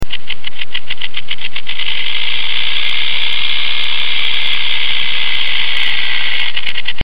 Rattlesnake